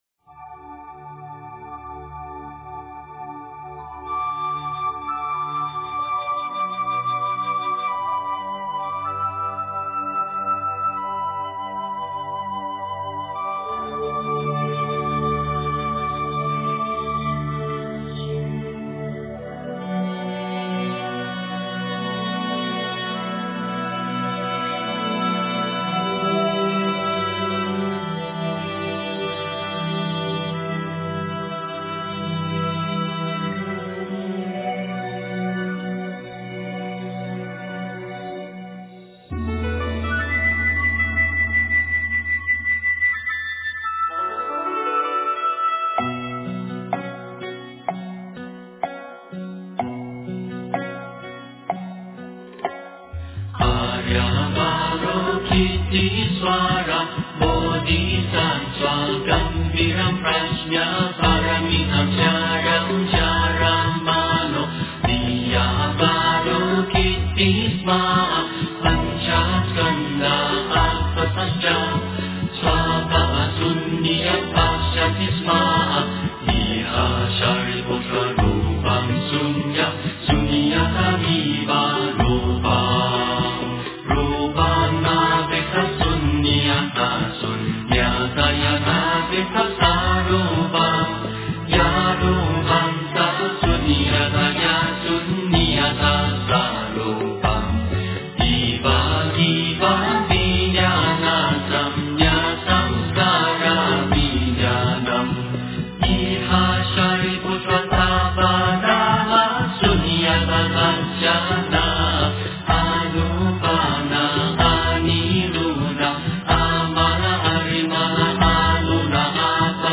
心经 诵经 心经--新韵传音 点我： 标签: 佛音 诵经 佛教音乐 返回列表 上一篇： 金刚萨埵心咒 下一篇： 大悲咒 相关文章 静止世界--佛教音乐(世界禅风篇) 静止世界--佛教音乐(世界禅风篇)...